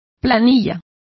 Complete with pronunciation of the translation of payroll.